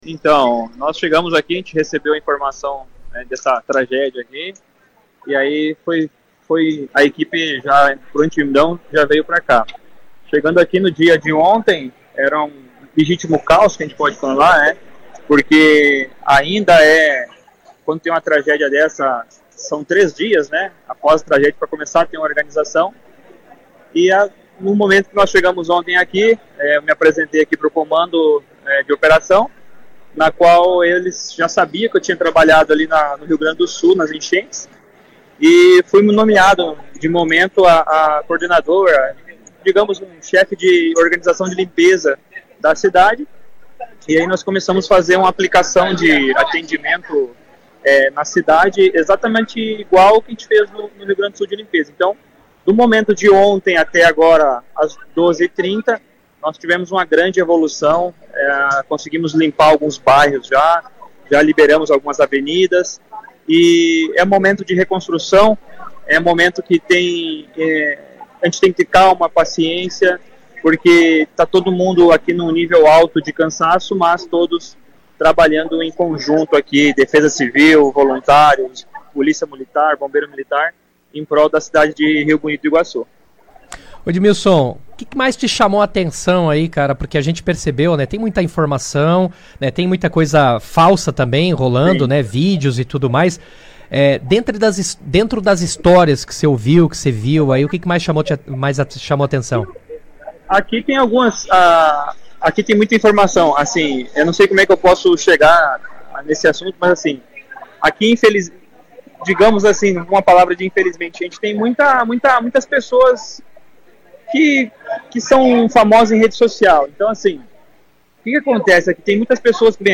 Ele participou ao vivo, por telefone, do Jornal RA 2ª Edição desta segunda-feira, 10, e relatou o cenário de destruição encontrado no município, além do intenso trabalho de limpeza e reconstrução que vem sendo realizado.